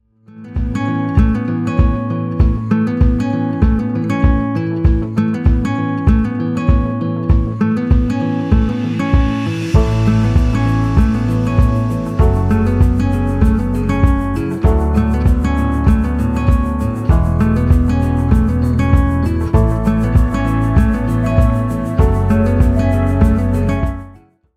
Featured in Instrumental Ringtones